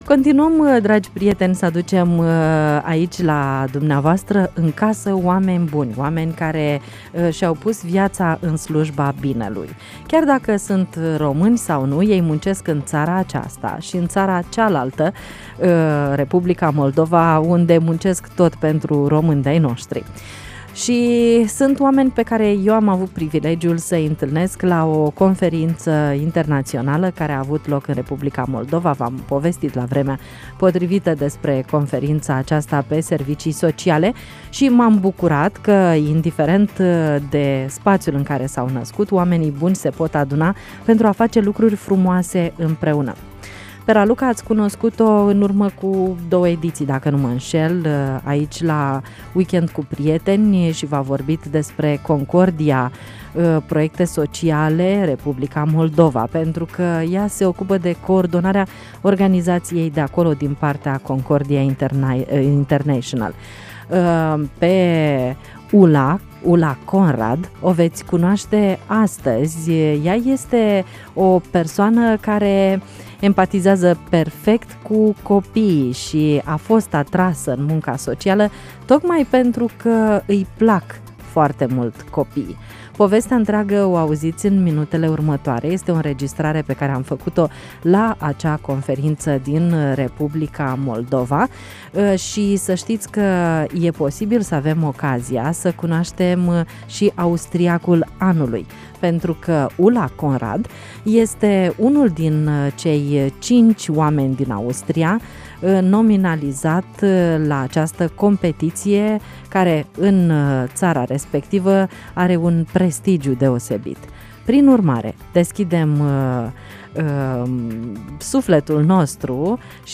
21-oct-17-W-p-intv-conf-Republica-Moldova-M-M.mp3